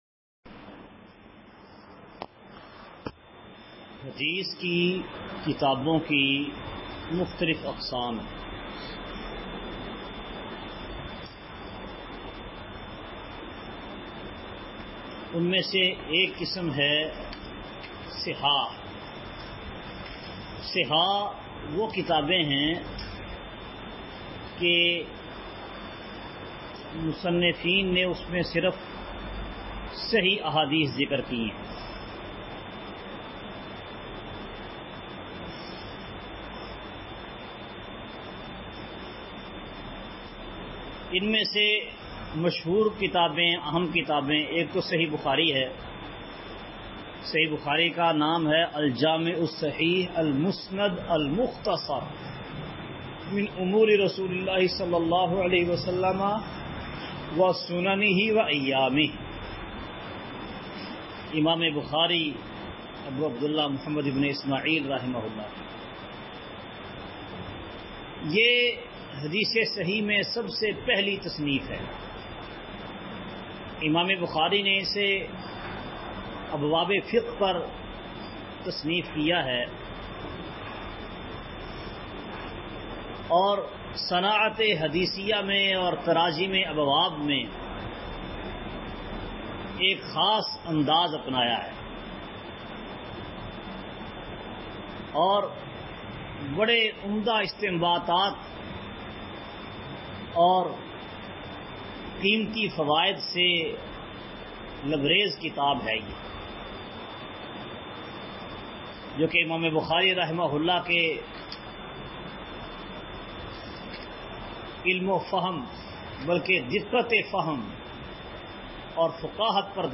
کتب احادیث سبق کا خلاصہ مختلف ادوار میں لکھی گئی حدیث کی مختلف قسم کی کتب کا تعارف تحميل mp3 × الحمد لله رب العالمين، والصلاة والسلام على سيد الأنبياء والمرسلين، أما بعد!